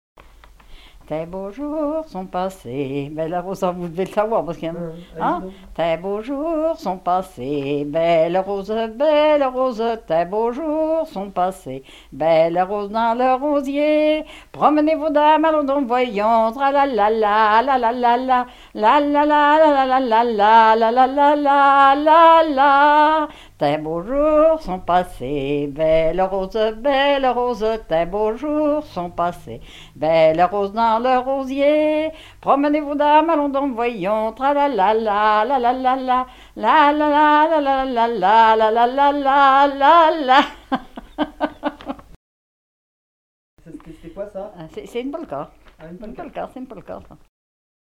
Chants brefs - A danser
danse : polka
Pièce musicale inédite